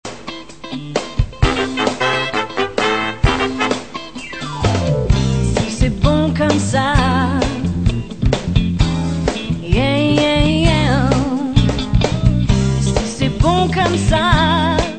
soul funk